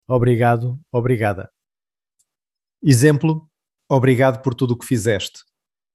Pronunciación de la palabra